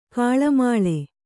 ♪ kāḷamāḷe